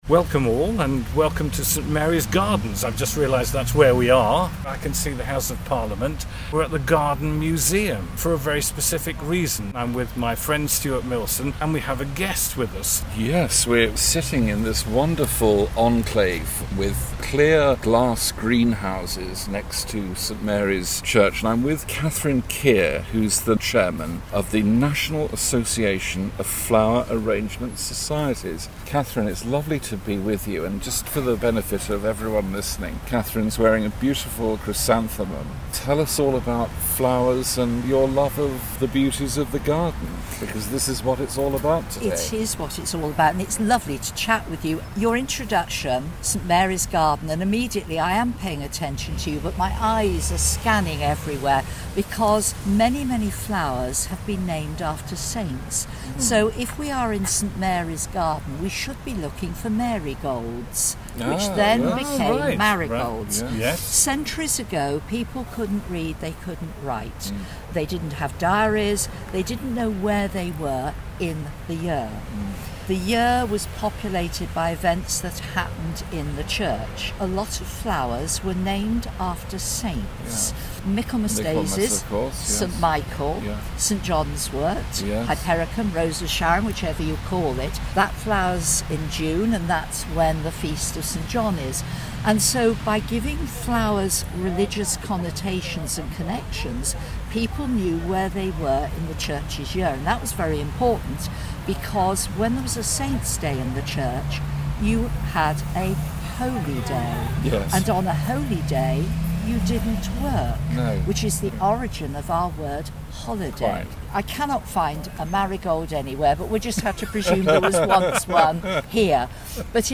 They have been friends for many years and now their chat on musical matters goes public.